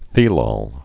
(thēlôl, -lōl, -lŏl)